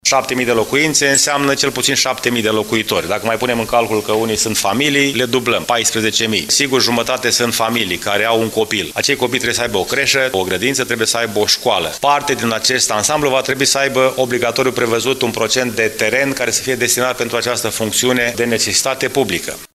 Primarul George Scripcaru arată că dezvoltatorii imobiliari trebuie să fie obligaţi să respecte câteva condiţii privind crearea de spaţii verzi, amenajarea de parcări de reşedinţă corelate cu numărul de apartamente construit și mai ales să asigure terenul necesar pentru viitoare unităţi de învăţământ: